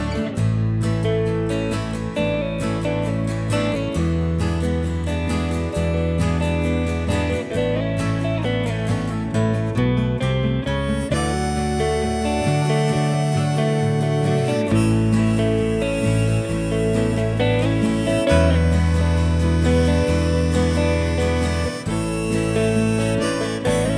no Backing Vocals Country (Male) 4:15 Buy £1.50